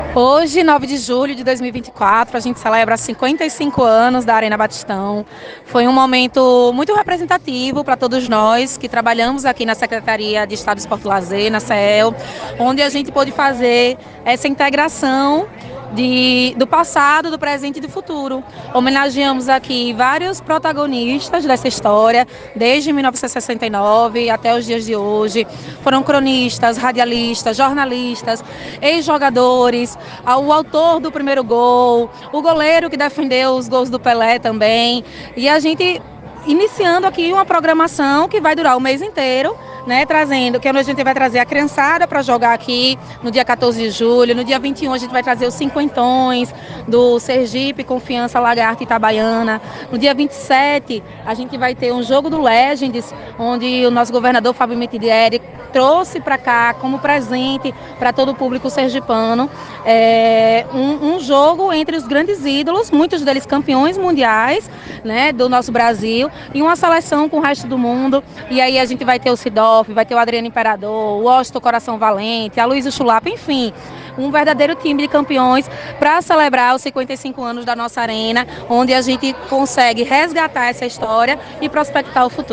Em homenagem ao aniversário de 55 anos do Estádio Lourival Baptista, o Batistão, o Governo de Sergipe, por meio da Secretaria de Estado do Esporte e Lazer (Seel), realizou uma celebração na manhã desta terça-feira, 9.